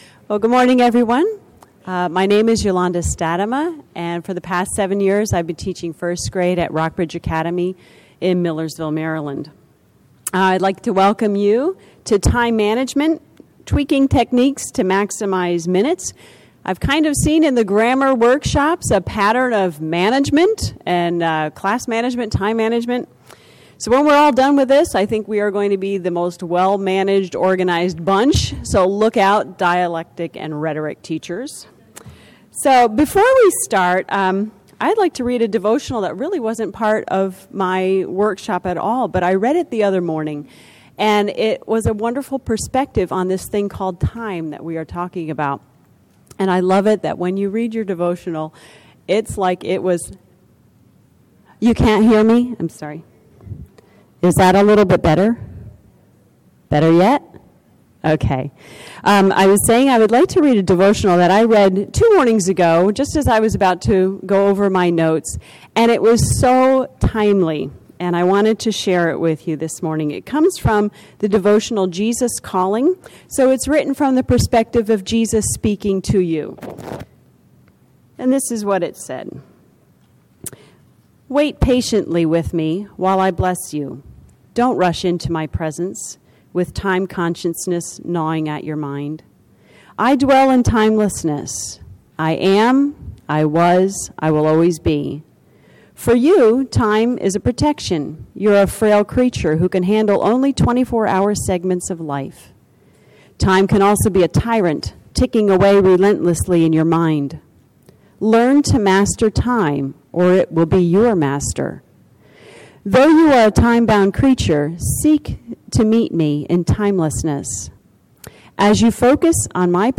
2012 Workshop Talk | 1:01:08 | Training & Certification